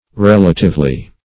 Relatively \Rel"a*tive*ly\, adv.